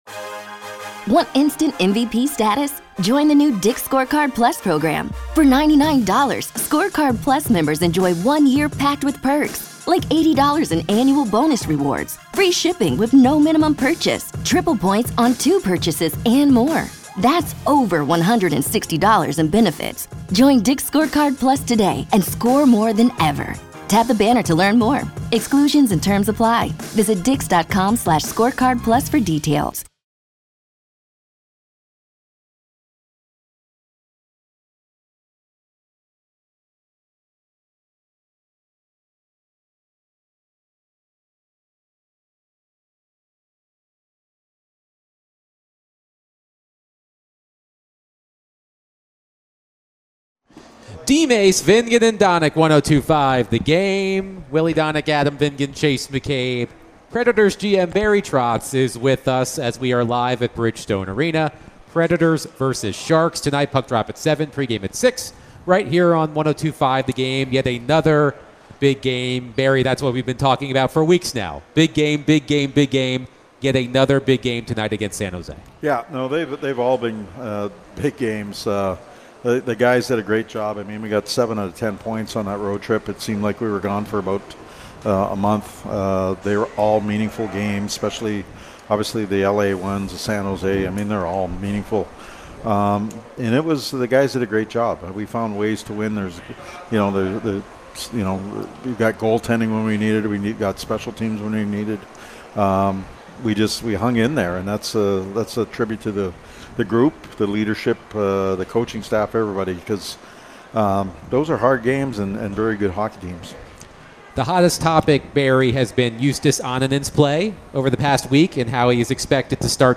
Nashville Predators General Manager Barry Trotz joined DVD for his weekly chat!